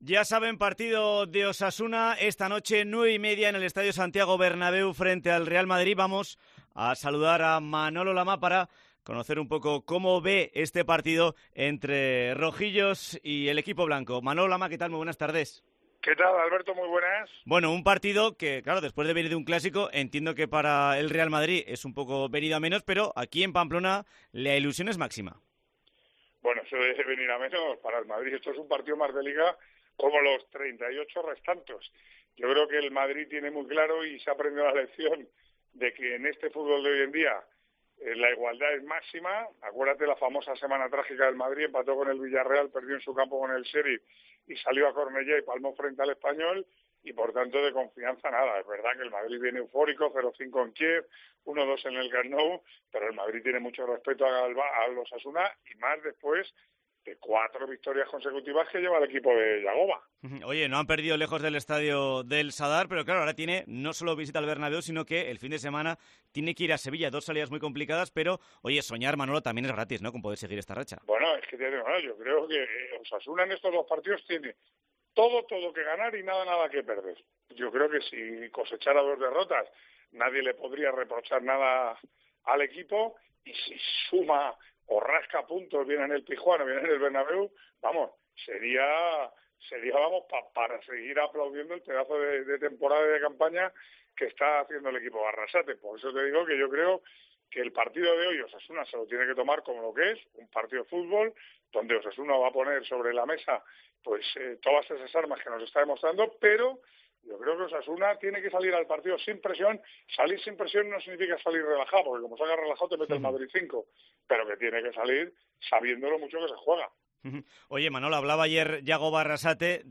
Entrevista con Manolo Lama antes del Real Madrid - Osasuna